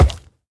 Minecraft Version Minecraft Version 1.21.5 Latest Release | Latest Snapshot 1.21.5 / assets / minecraft / sounds / mob / piglin_brute / step3.ogg Compare With Compare With Latest Release | Latest Snapshot